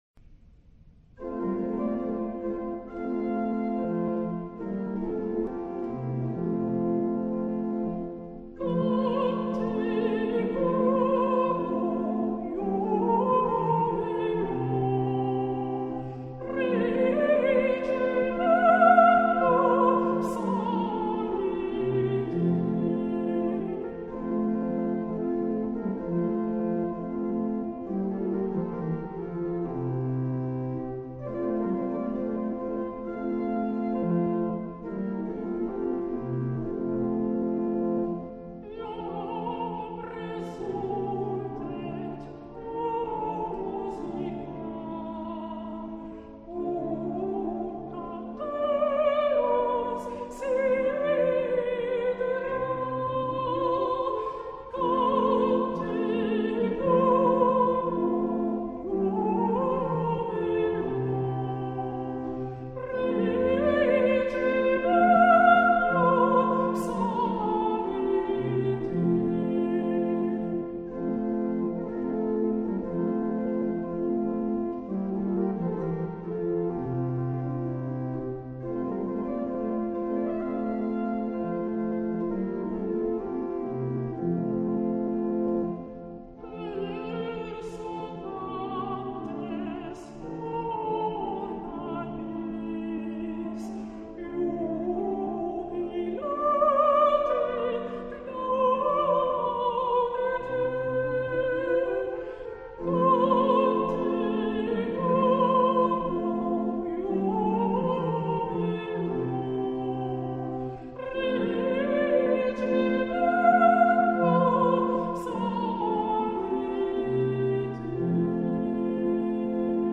mp3 versions chantées
Soprano
Soprano F Major Bpm 129